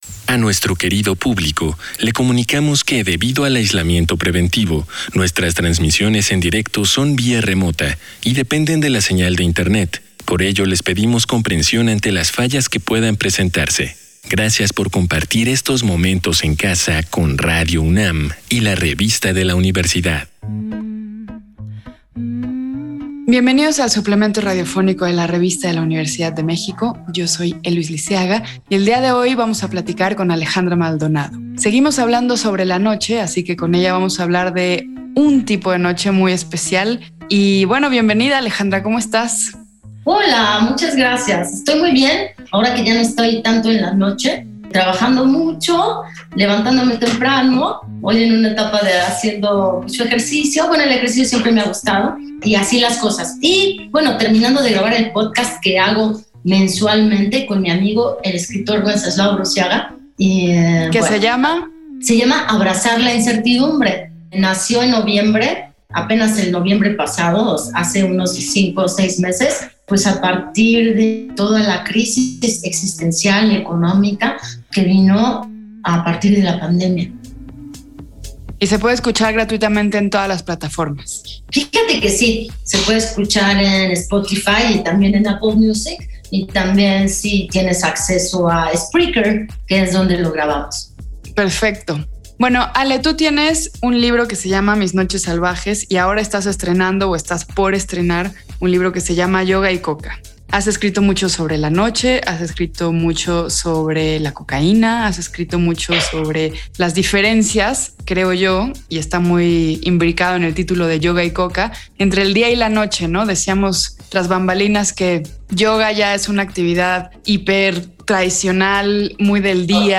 Cargar audio Este programa es una coproducción de la Revista de la Universidad de México y Radio UNAM. Fue transmitido el jueves 10 de junio de 2021 por el 96.1 FM.